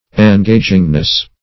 En*ga"ging*ness, n.